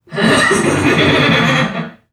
NPC_Creatures_Vocalisations_Robothead [41].wav